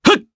Clause-Vox_Attack1_jp.wav